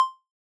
button click.wav